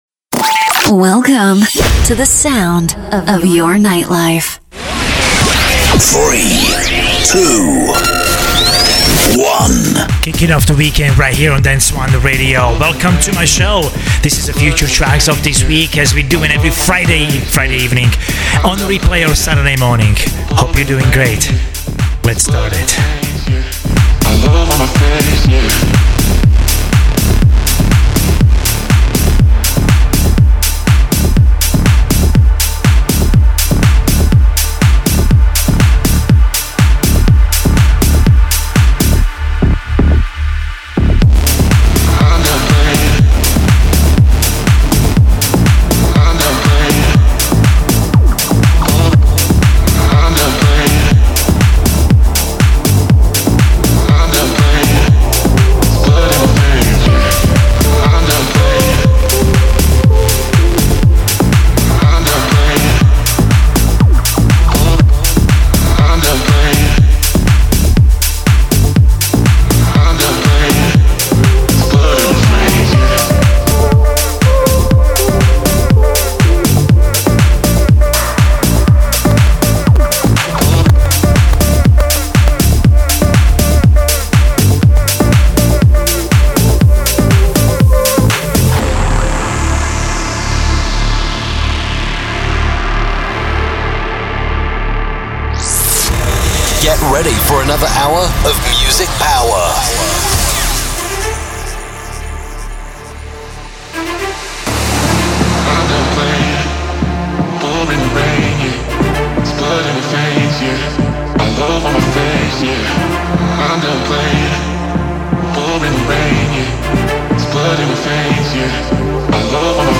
Dance anthems that rule the dance and electronic scene